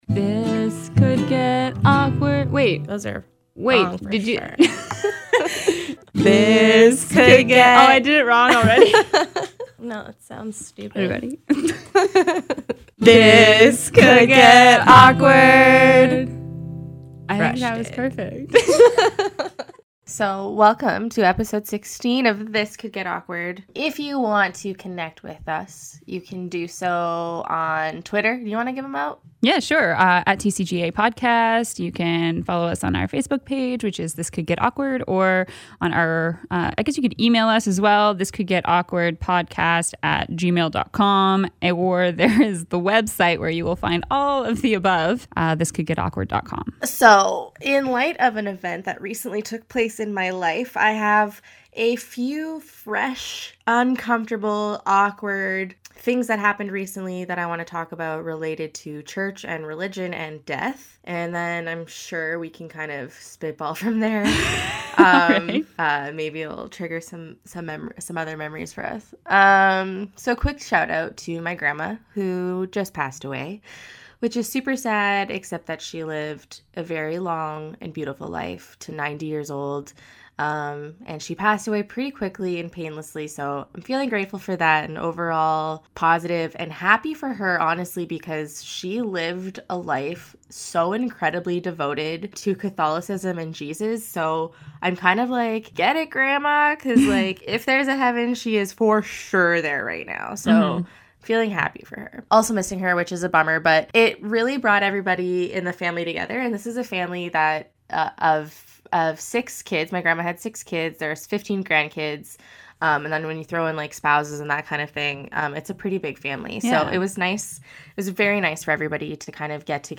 This Could Get Awkward » Page 50 of 65 » A podcast by two awesome women.